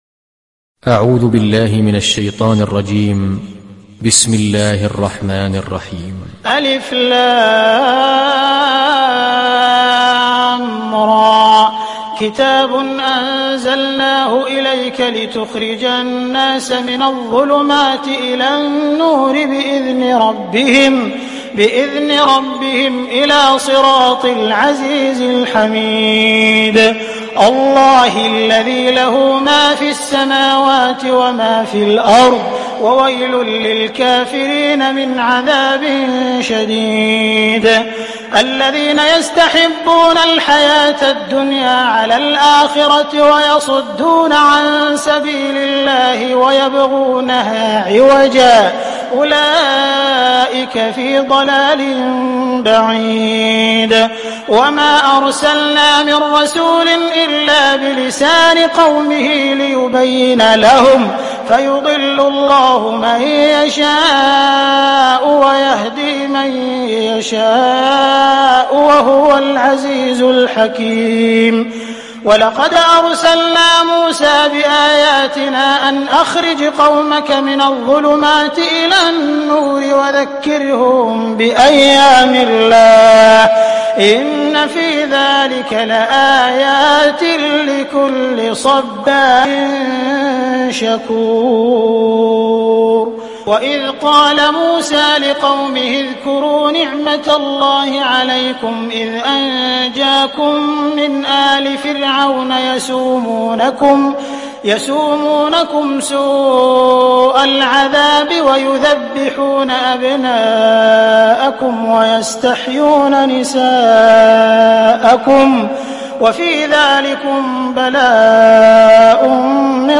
تحميل سورة إبراهيم mp3 بصوت عبد الرحمن السديس برواية حفص عن عاصم, تحميل استماع القرآن الكريم على الجوال mp3 كاملا بروابط مباشرة وسريعة